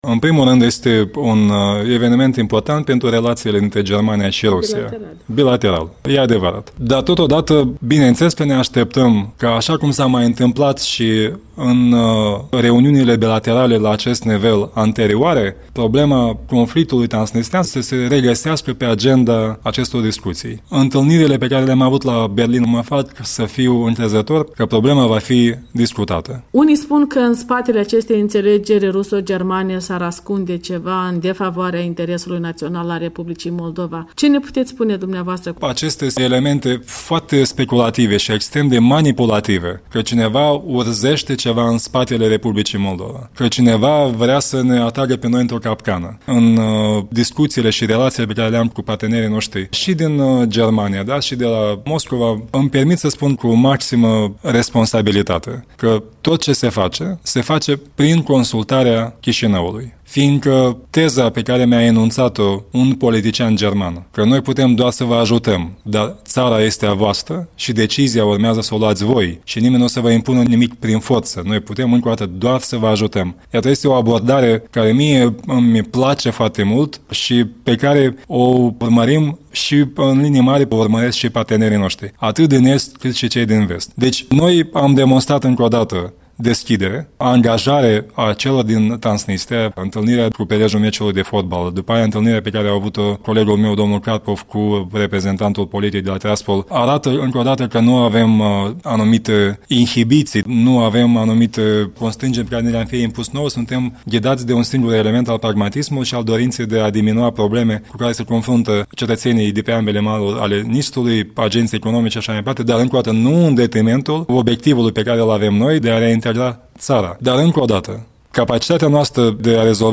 Iurie Leancă, șeful diplomației moldovene